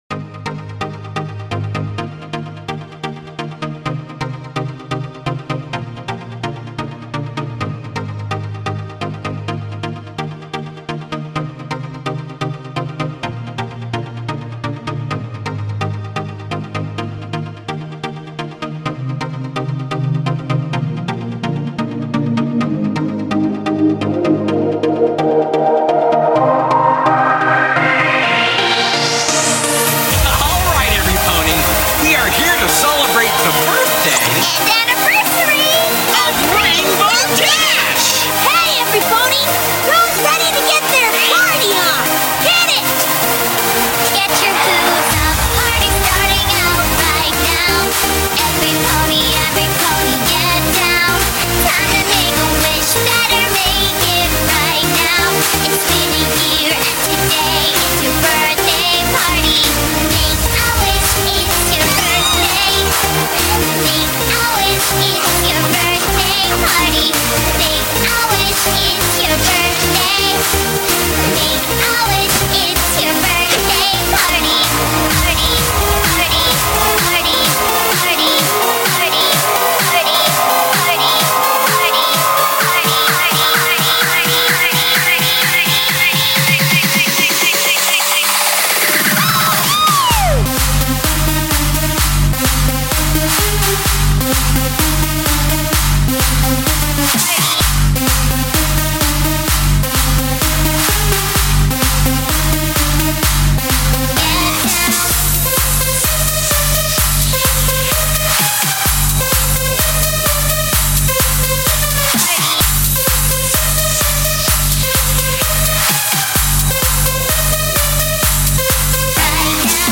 Genre: Big Room House BPM: 128